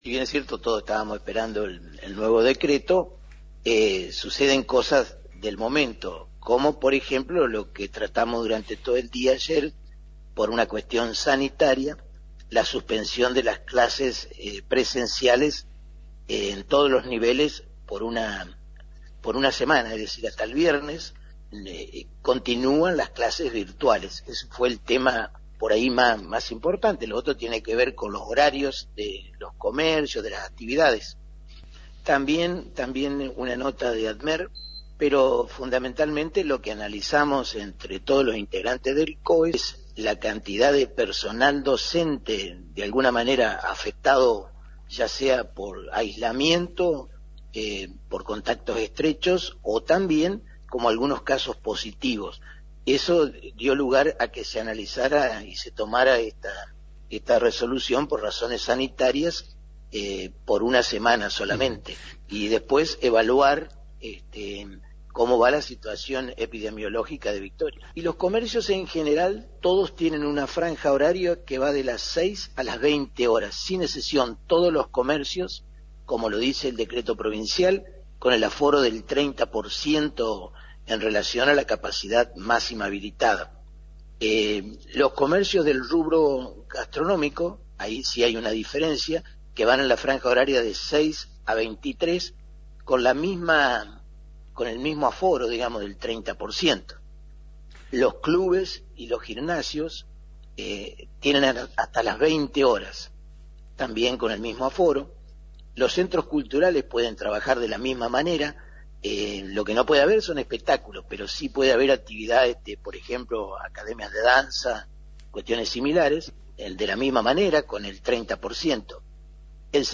Intendente Domingo Maiocco en FM 90.3